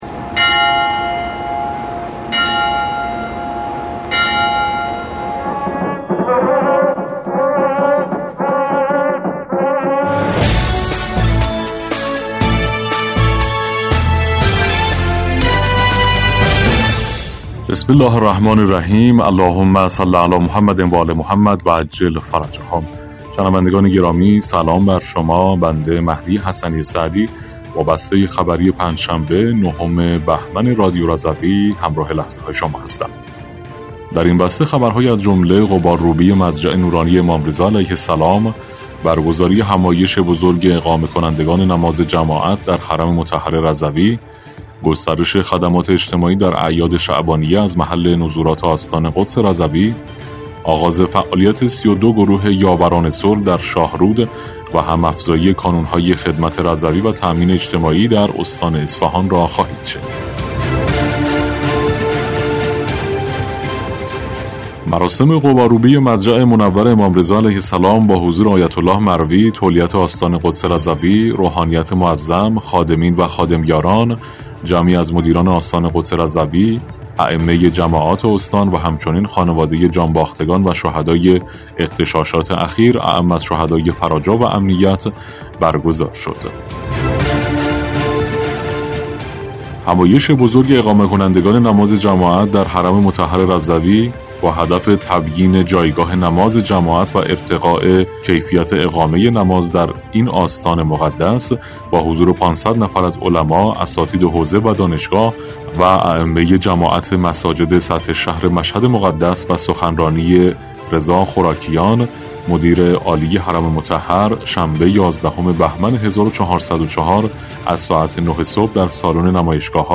بسته خبری ۹ بهمن ۱۴۰۴ رادیو رضوی؛